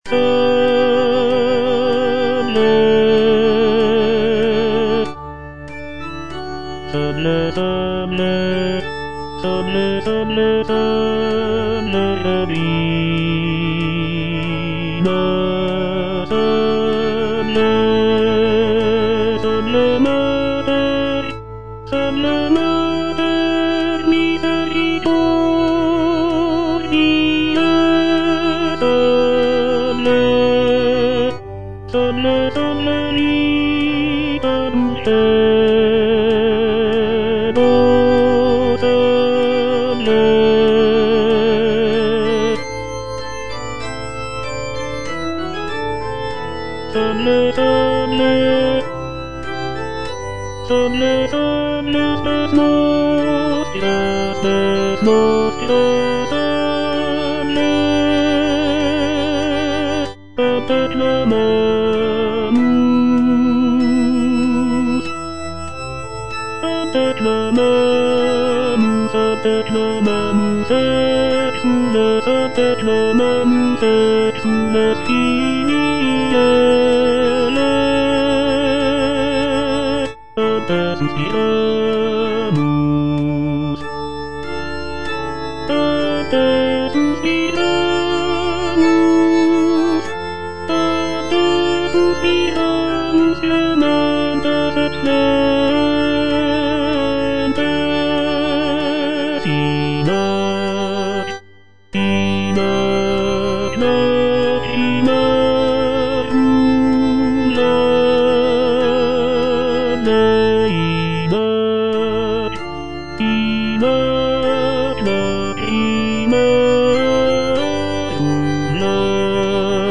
G.F. SANCES - SALVE, REGINA Tenor (Voice with metronome) Ads stop: auto-stop